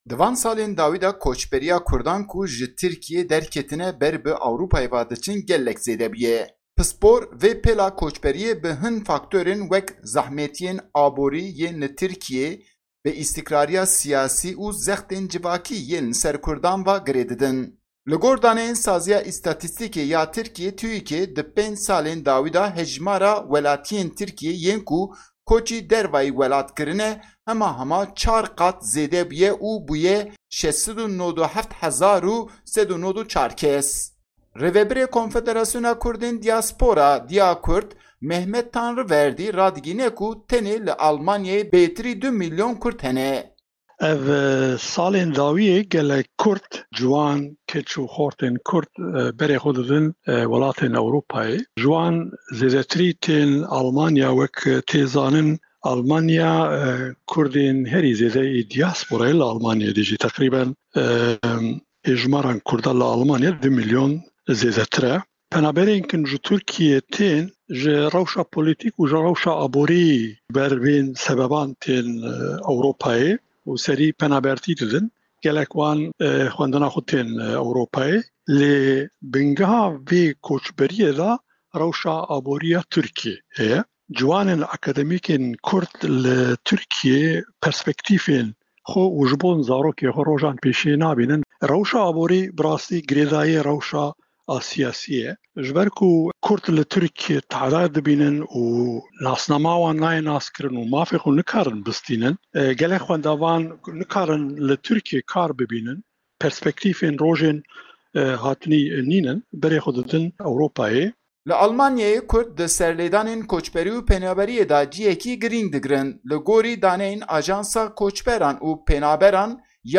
Raporta